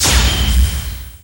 Magic_SpellImpact28.wav